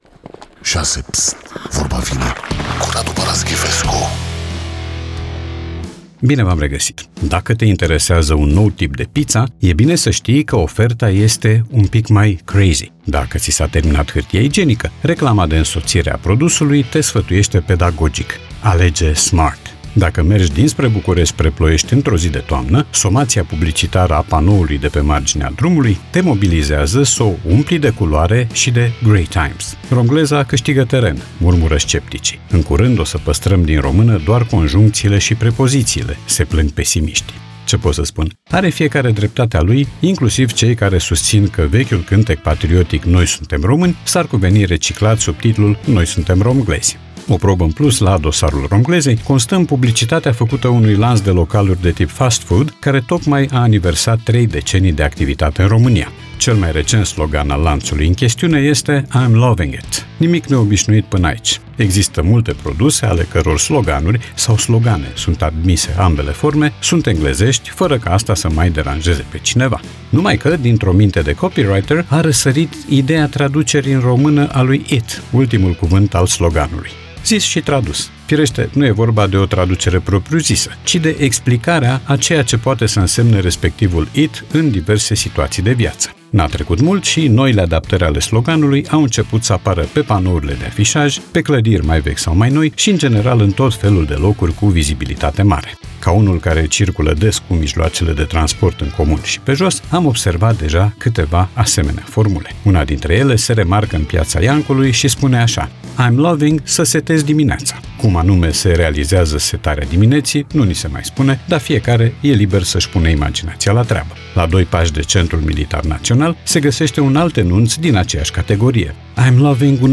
Podcast 17 iunie 2025 Vezi podcast Vorba vine, cu Radu Paraschivescu Radu Paraschivescu iti prezinta "Vorba vine", la Rock FM.